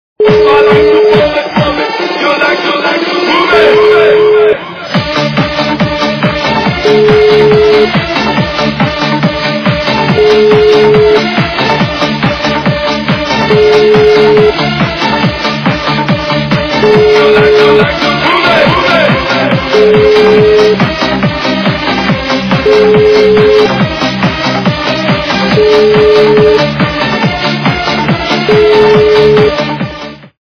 западная эстрада
качество понижено и присутствуют гудки.